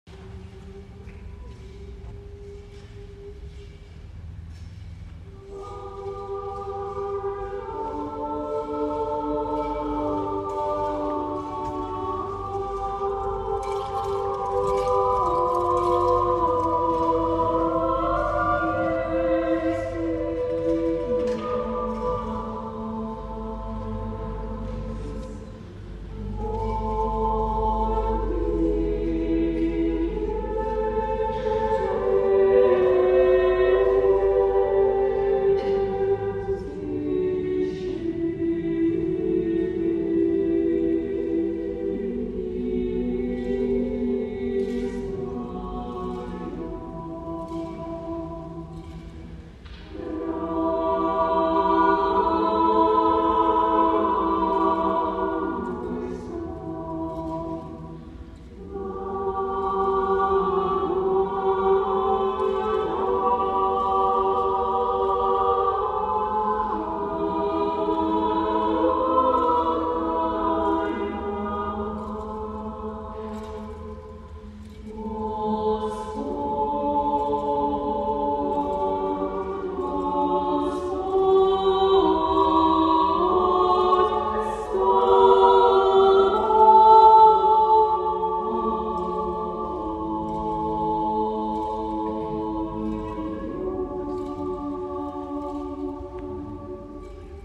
В канун праздника Благовещения Пресвятой Богородицы в нашей обители совершено праздничное вечернее богослужение
Хор Богородице-Рождественского монастыря. "Архангельский глас"